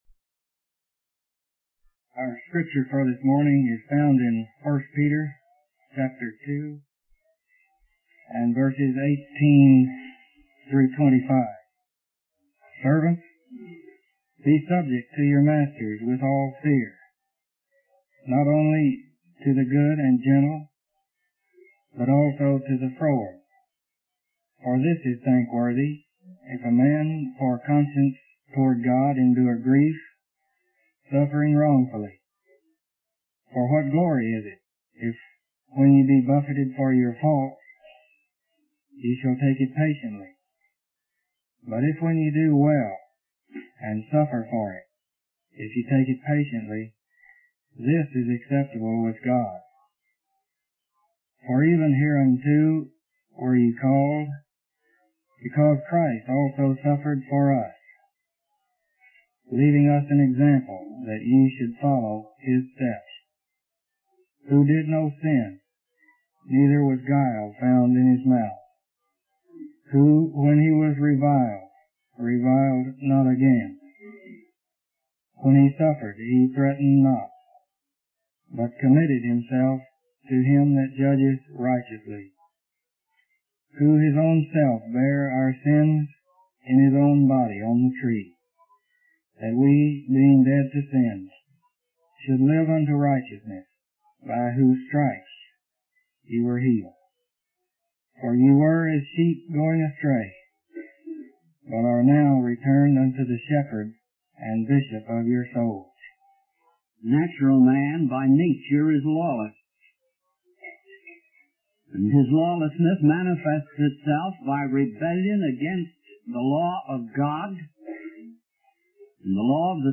In this sermon, the preacher focuses on the scripture from 1 Peter 2:18-25, which talks about the importance of servants being subject to their masters with fear and patience, even when suffering wrongfully. The preacher emphasizes that it is commendable to endure suffering for doing good, just as Christ suffered for us as an example.